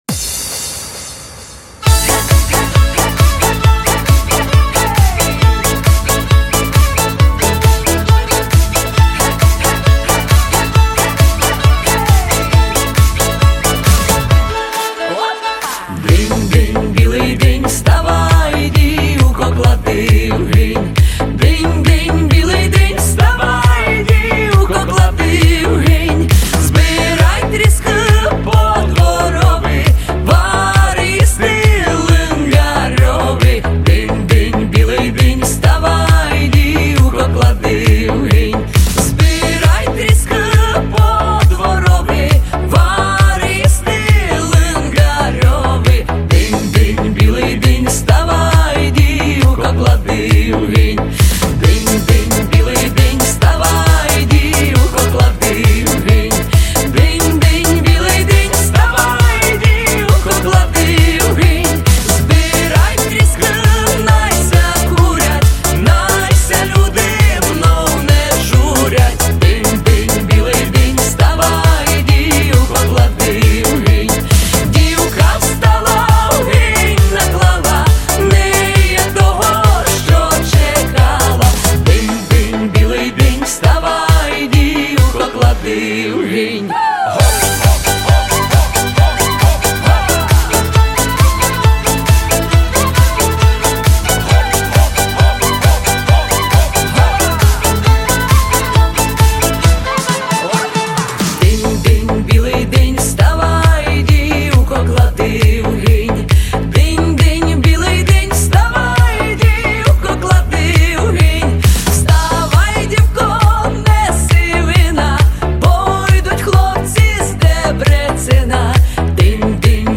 мікси народних пісень
запального фольку